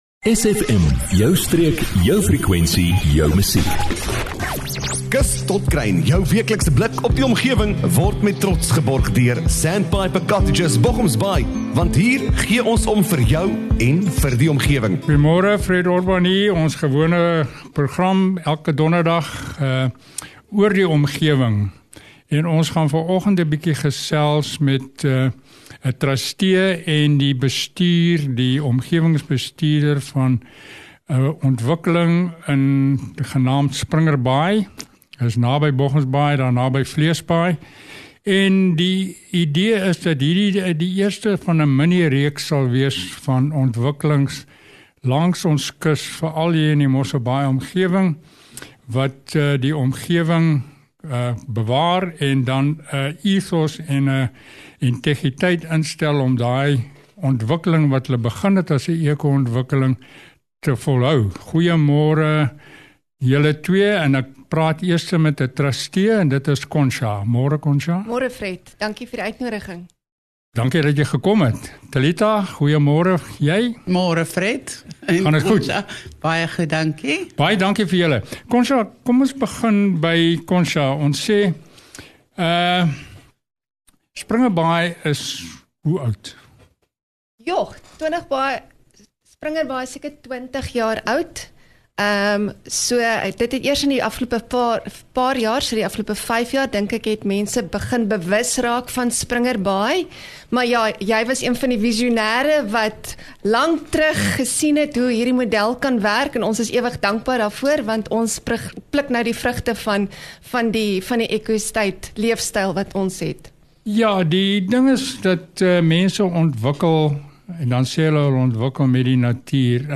'n Insiggewende onderhoud met bestuur verteenwoordigers van SPRINGERBAAI ECO ESTATE.